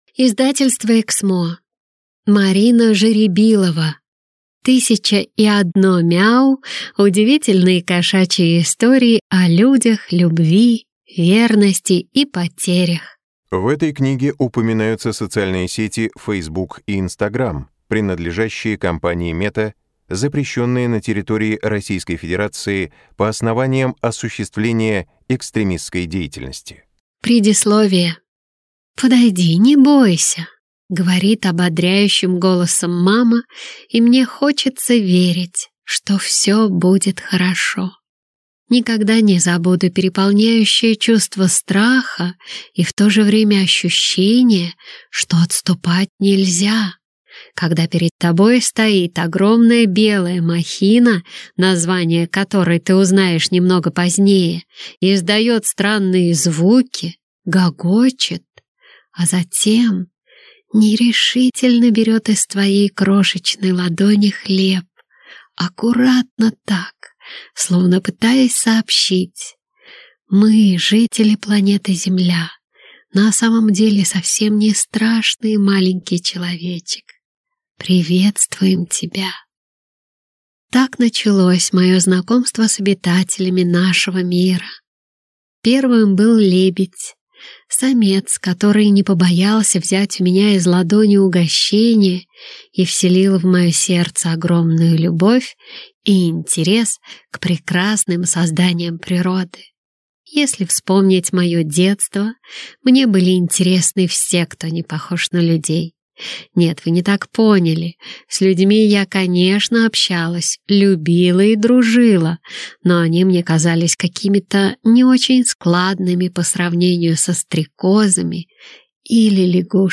Аудиокнига Тысяча и одно мяу. Удивительные кошачьи истории о людях, любви, верности и потерях | Библиотека аудиокниг